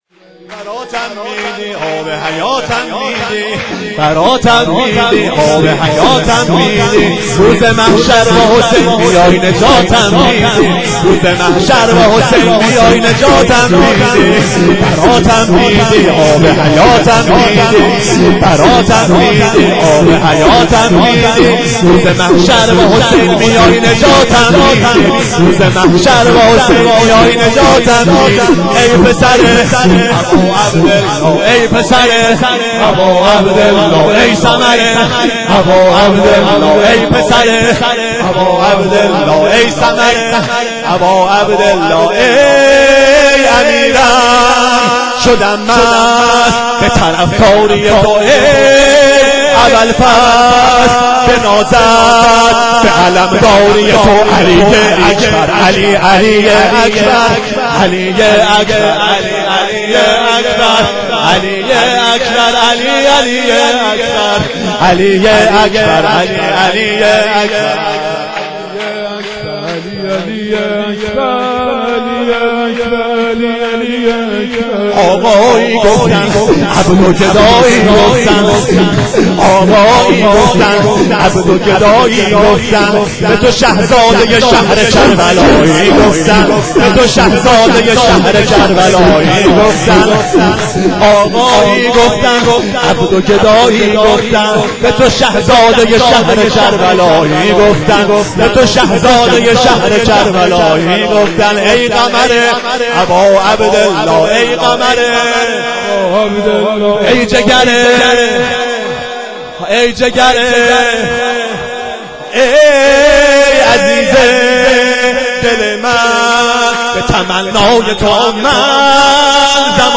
شور
شام میلاد حضرت علی اکبر 1392